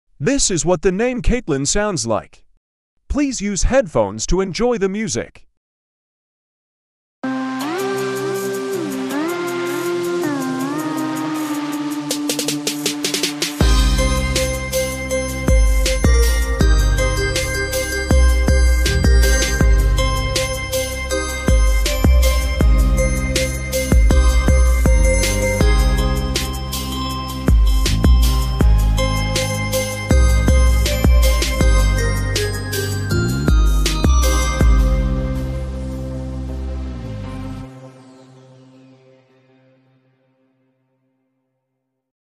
as midi art